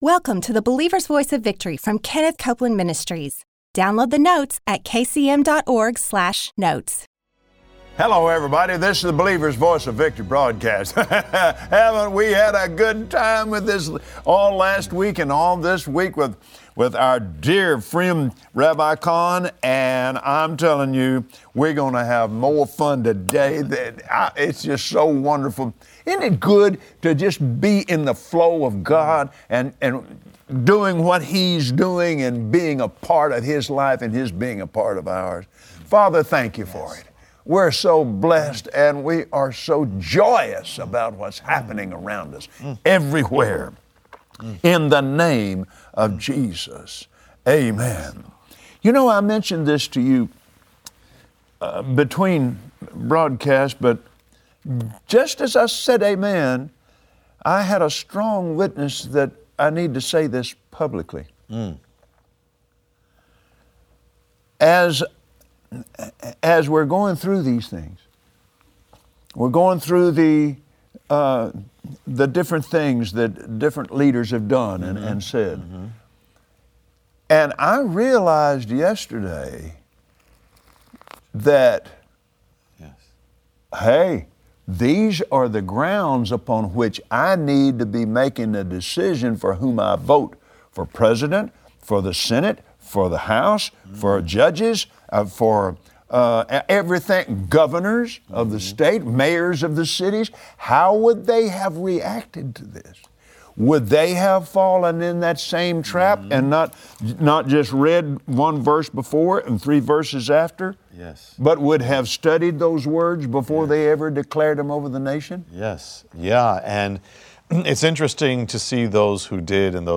Today, on the Believer’s Voice of Victory, Kenneth Copeland and his special guest, Rabbi Jonathan Cahn, bring a message of restoration. God has made a way for every man to return unto Him.